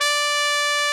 Index of /90_sSampleCDs/Roland L-CD702/VOL-2/BRS_Tpt Cheese/BRS_Cheese Tpt
BRS TRUMPE0J.wav